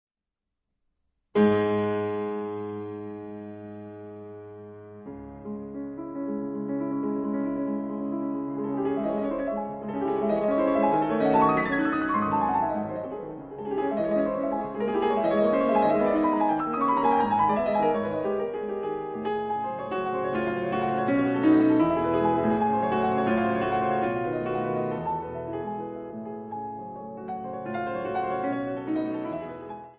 fortepian / piano